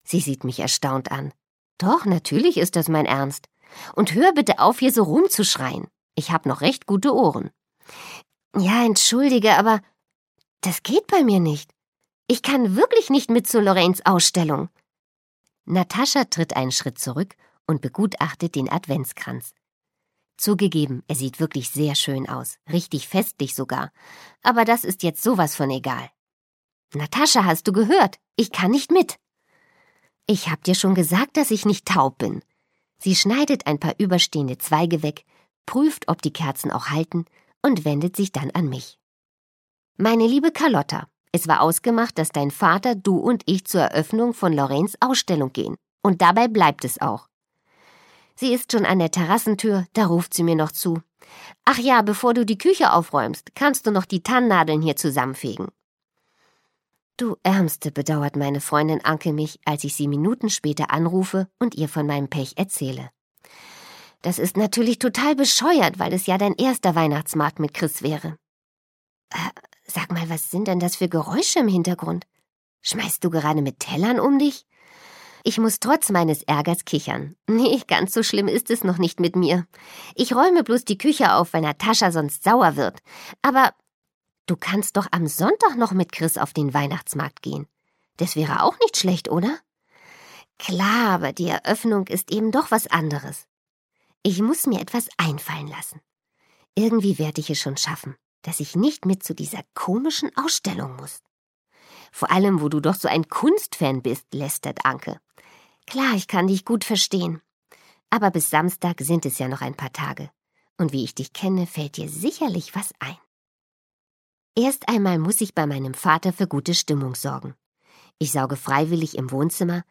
Freche Mädchen: Küsse unterm Tannenbaum - Thomas Brinx - Hörbuch - Legimi online